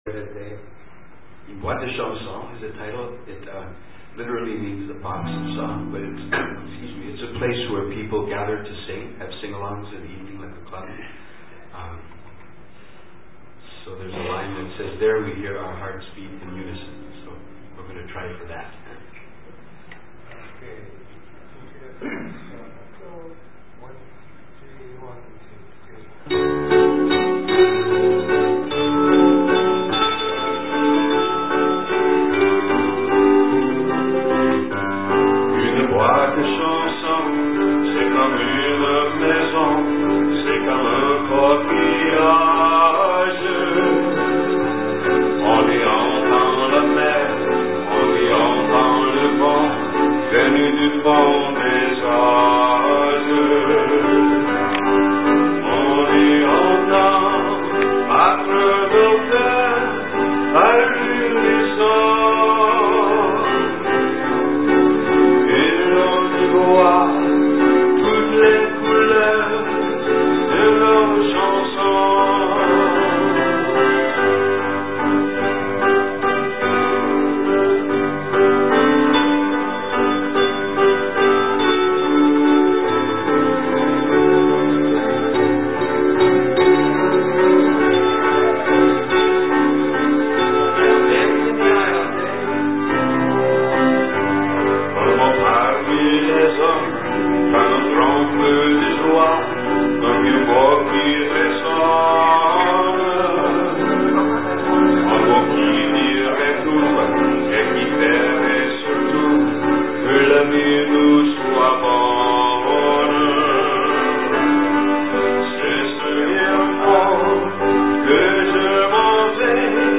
Keyboard
performing French-Canadian songs
sing beautiful French song
above - a large audience came to enjoy the evening of music and comedy